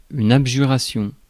Ääntäminen
IPA: [ab.ʒy.ʁa.sjɔ̃]